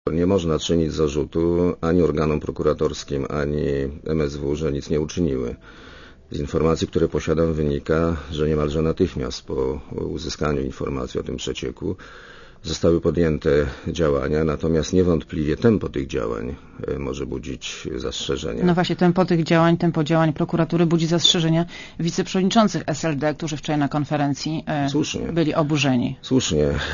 Leszek Miller w Radiu Zet (RadioZet)
Mówi premier Leszek Miller (104 KB)